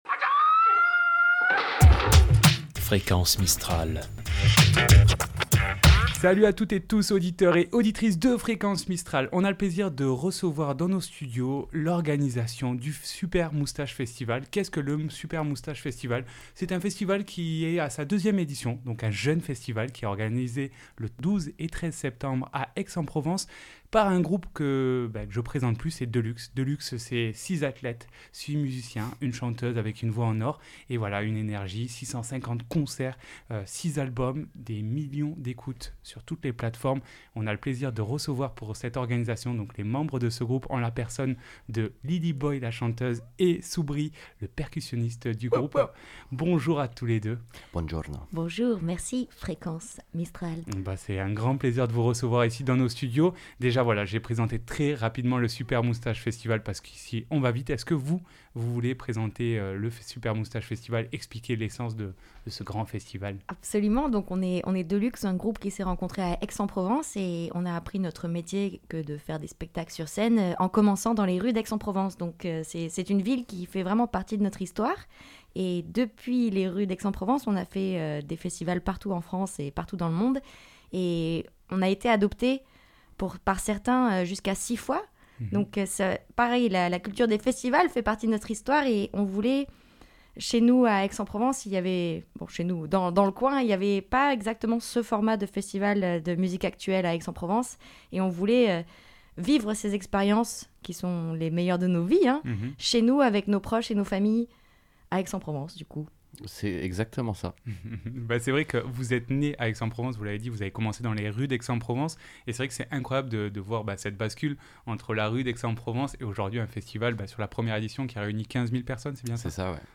ITW SUPER MOUSTACHE.mp3 (32.6 Mo)